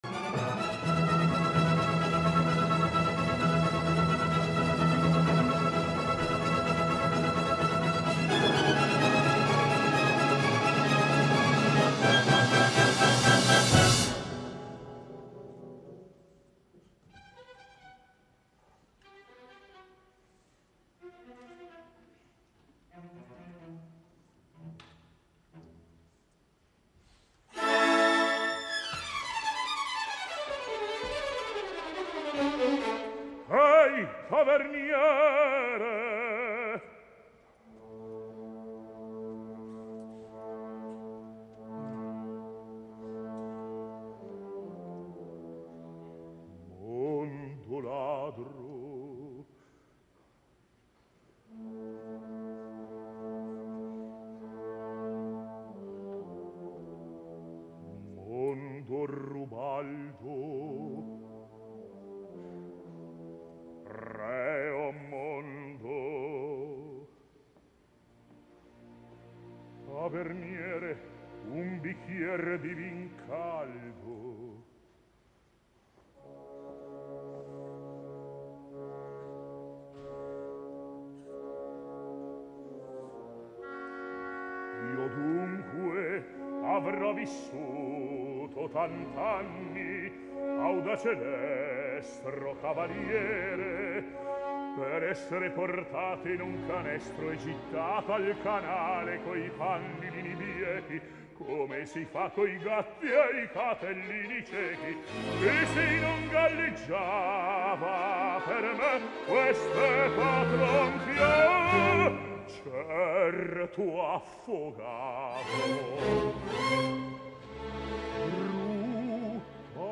FALSTAFF A LA SCALA 2013 | IN FERNEM LAND
És poc matisat, massa genèric i en aquest sentit vulgar.
I ara a l’inici del tercer acte “mondo ladro” un dels moments més reeixits, tant en la interpretació del baríton com de la direcció de Harding, malgrat que els petits problemes vocals de Maestri s’evidencies per aquí i per allà.